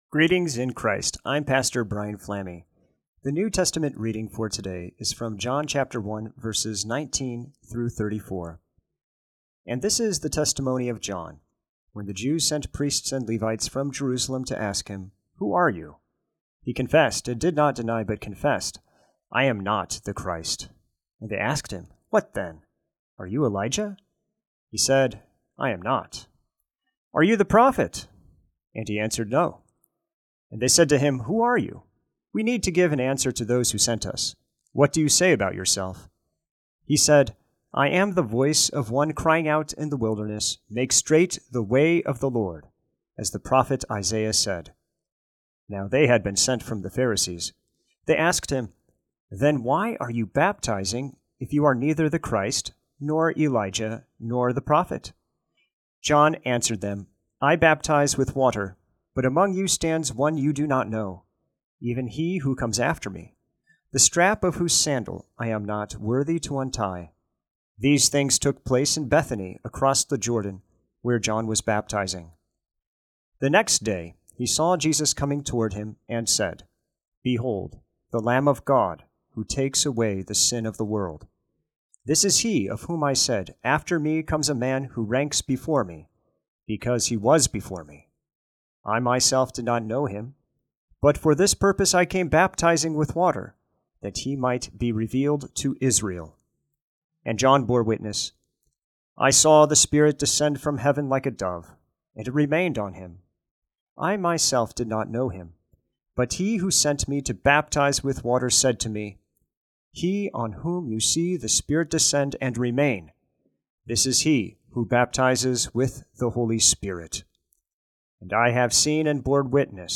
Morning Prayer Sermonette: John 1:19-34
Hear a guest pastor give a short sermonette based on the day’s Daily Lectionary New Testament text during Morning and Evening Prayer.